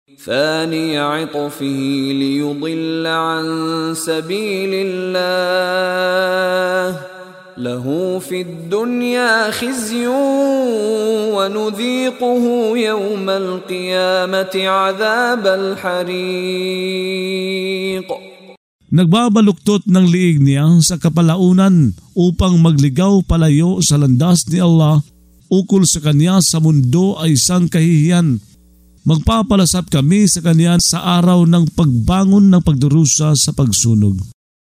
Pagbabasa ng audio sa Filipino (Tagalog) ng mga kahulugan ng Surah Al-Haj ( Ang Pilgrimahe ) na hinati sa mga taludtod, na sinasabayan ng pagbigkas ng reciter na si Mishari bin Rashid Al-Afasy.